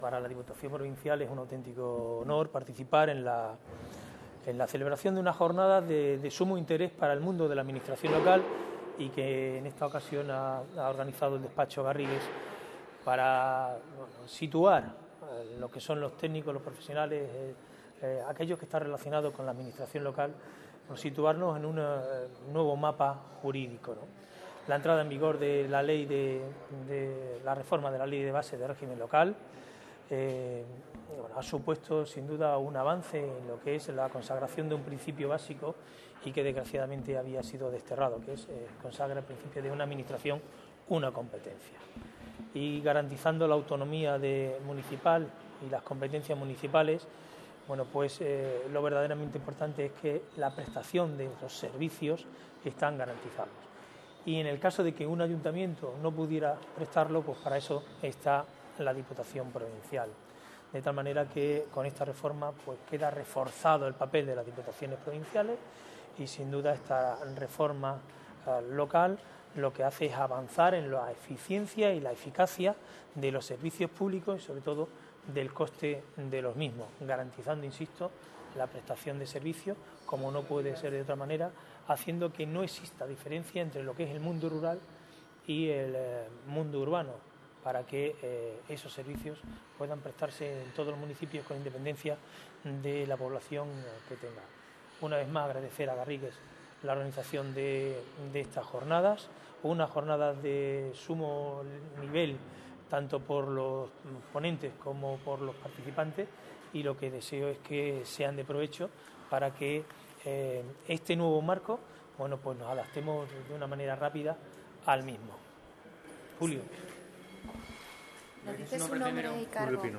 CORTES DE VOZ
12/02/2014-. El presidente de la Diputación de Cáceres, Laureano León Rodríguez, ha inaugurado este miércoles la ‘Jornada sobre Reforma de la Administración Local’, organizada por Garrigues Abogados en colaboración con la Institución Provincial.